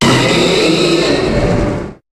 Cri de Méga-Braségali dans Pokémon HOME.
Cri_0257_Méga_HOME.ogg